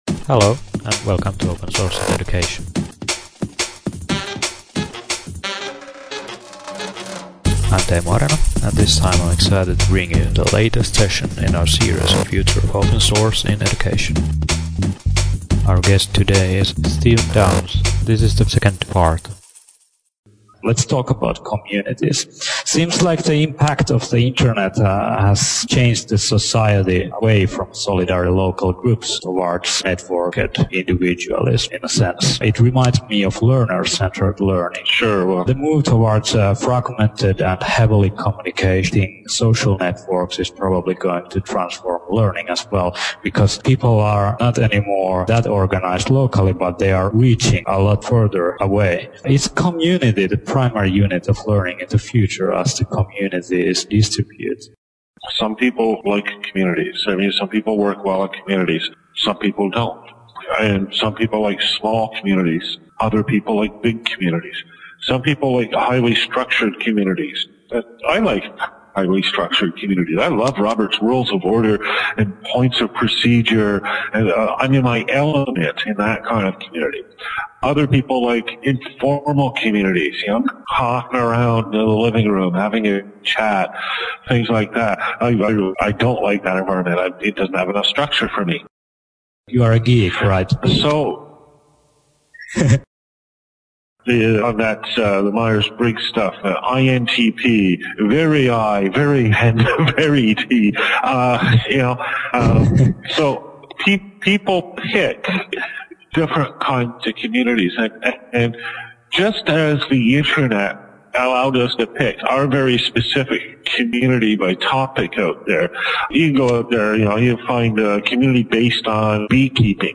The sound quality is so-so, but it was the first experience for both of us recording a Skype conversation.